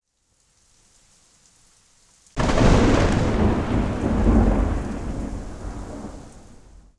Donner
donner